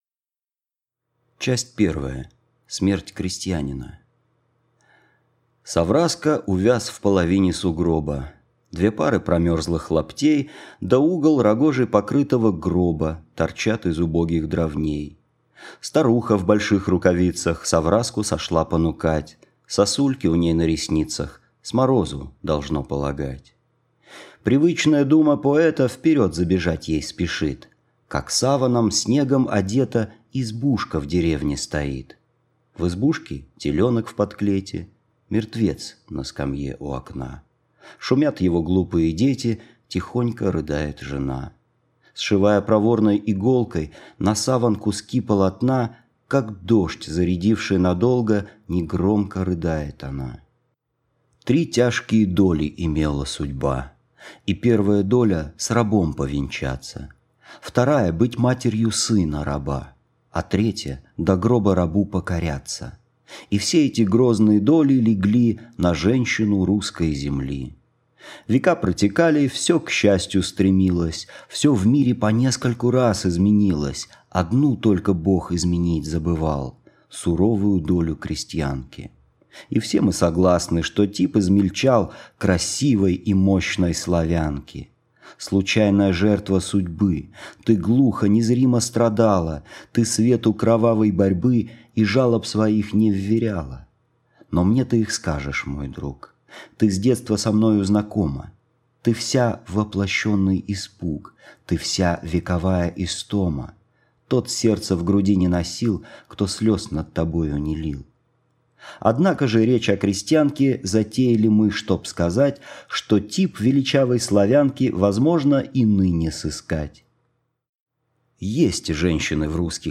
Аудиокнига Мороз, Красный нос | Библиотека аудиокниг